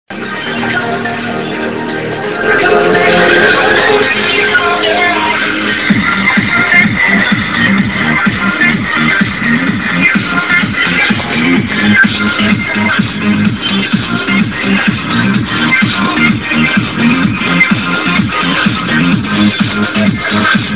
help id club track pls